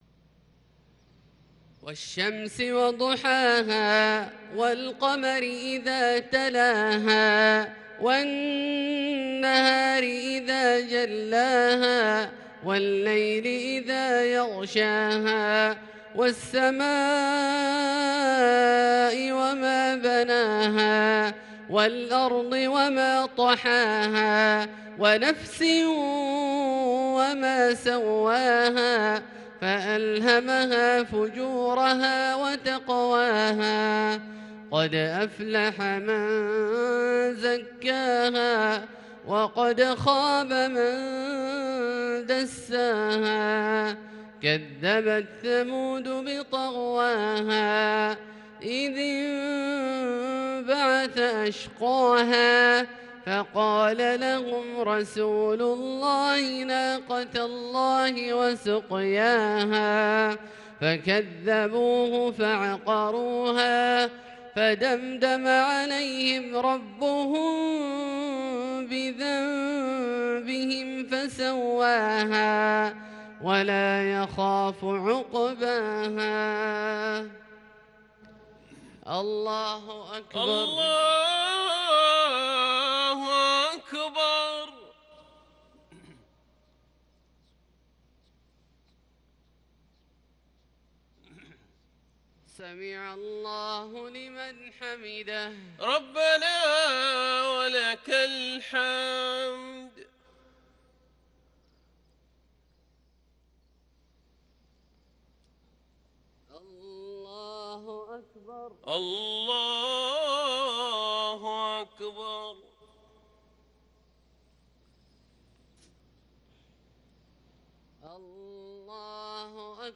مغرب ٦-٣-١٤٤٢ سورتي الشمس والتين > ١٤٤٢ هـ > الفروض - تلاوات عبدالله الجهني